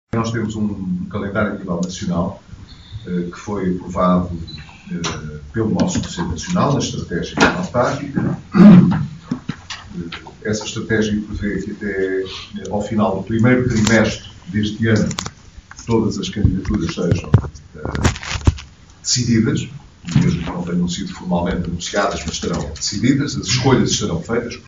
Declarações de Pedro Passos Coelho em Viana do Castelo